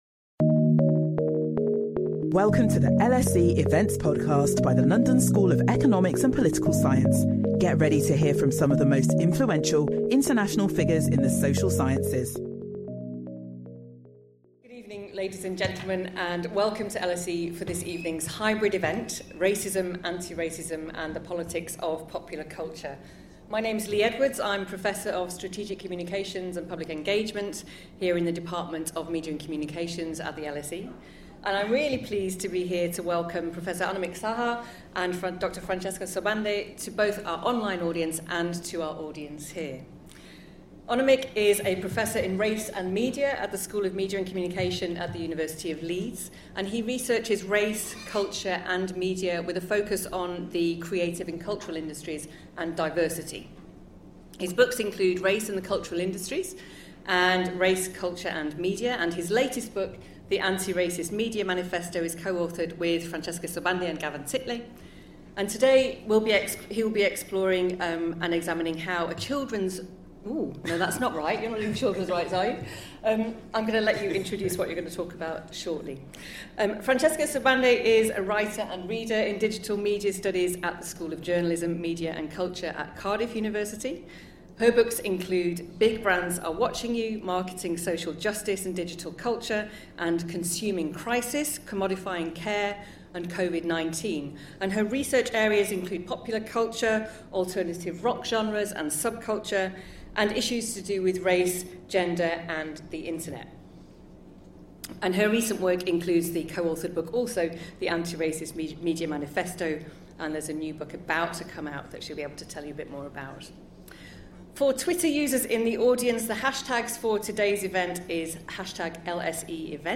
This joint talk reflects on current practices of "othering" in popular media and probes the nature and meaning of media diversity amidst far right appeals to media representation. These practices point to shifts in whom a plural media system can and ought to serve and why.